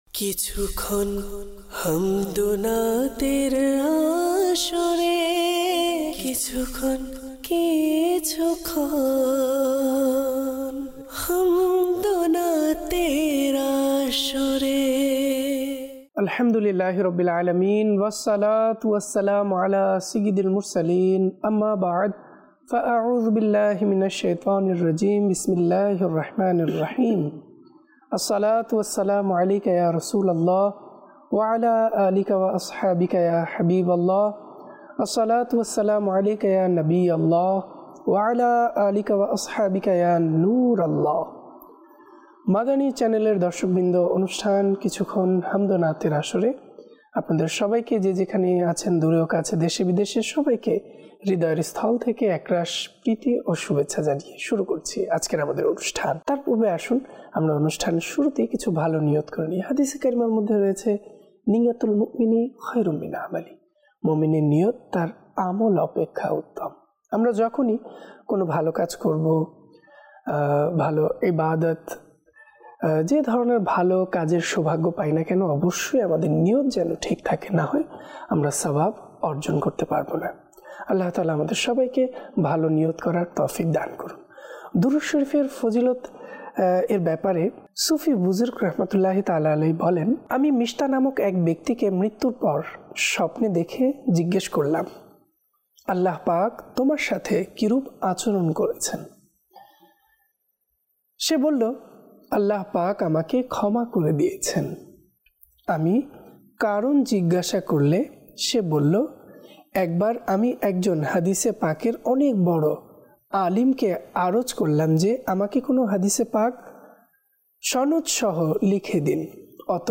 কিছুক্ষণ হামদ ও নাতের আসরে EP# 328